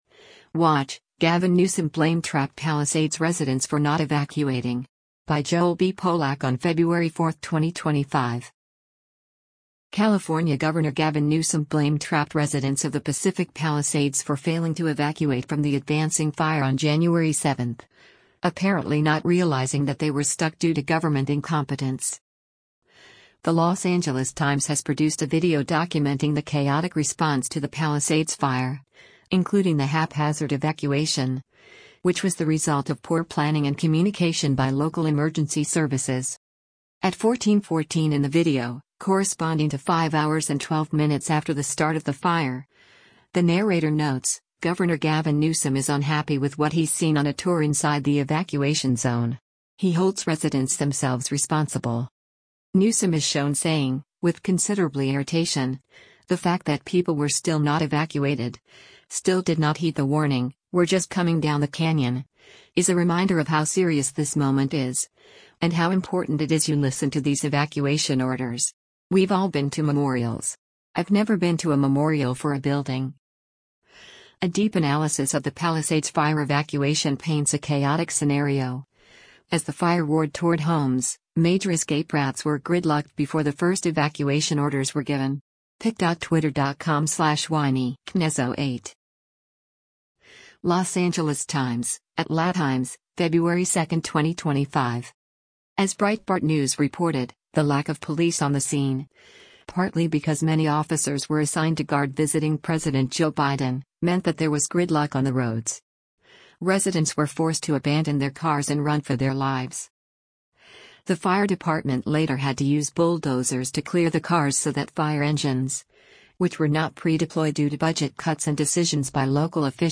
Newsom is shown saying, with considerably irritation: “The fact that people were still not evacuated, still did not heed the warning, were just coming down the canyon, is a reminder of how serious this moment is, and how important it is you listen to these evacuation orders.